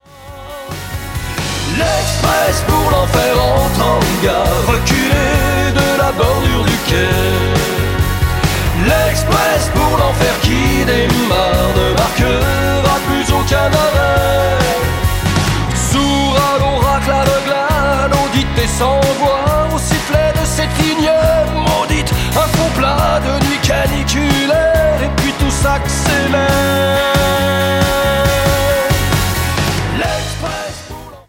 Basse
Batterie
Orgue